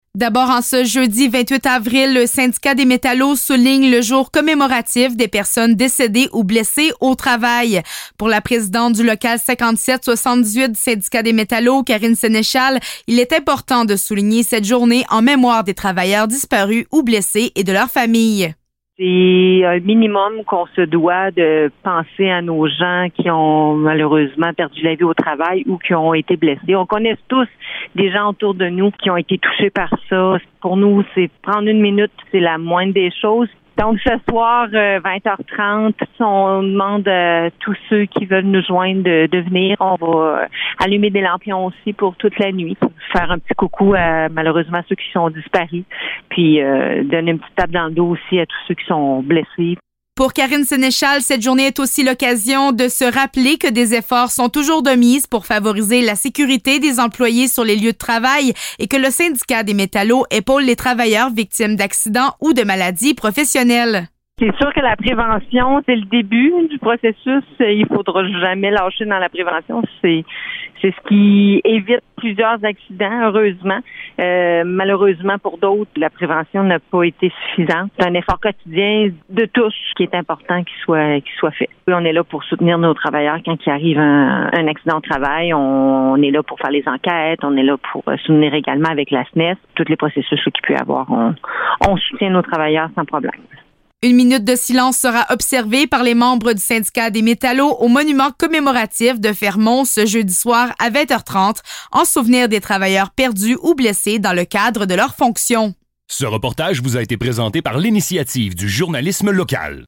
Reportage-28-avril-Nouvelles-CFMF-ID-IJL.mp3